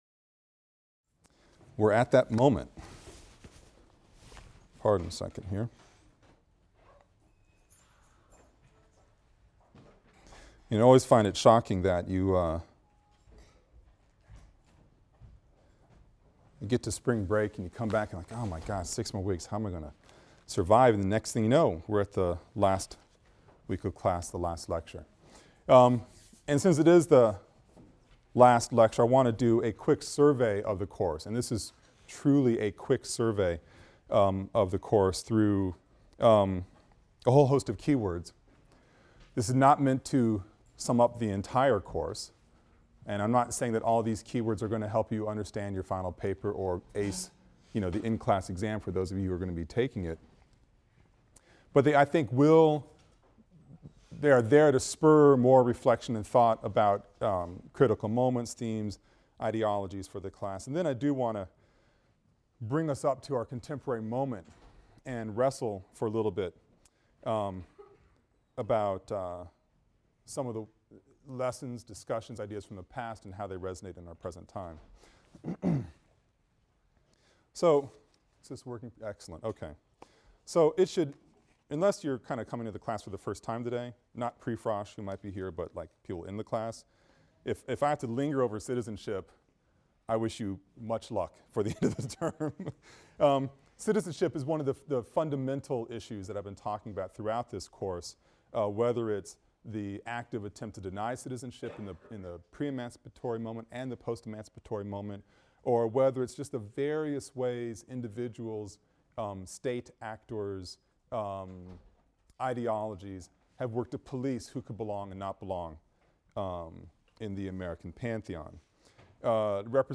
AFAM 162 - Lecture 25 - Who Speaks for the Race? (continued) | Open Yale Courses